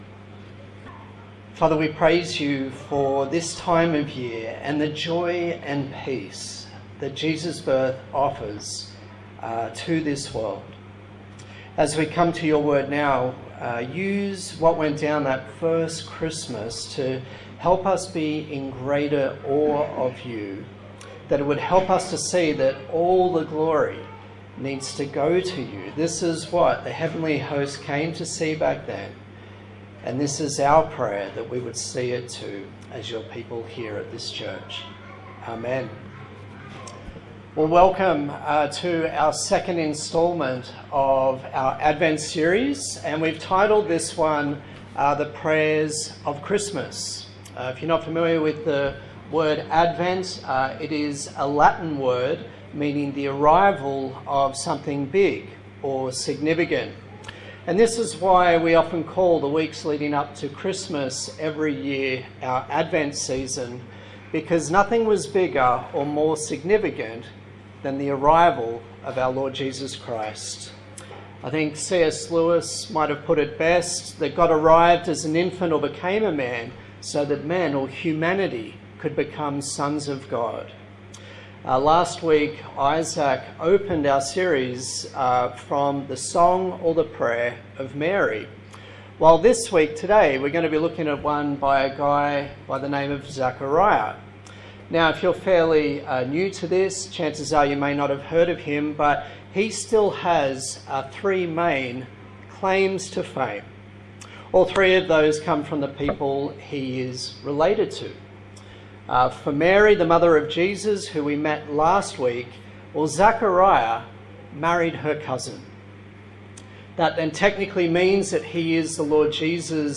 A sermon in the series on The Prayers of Christmas from the Gospel of Luke
Service Type: Sunday Morning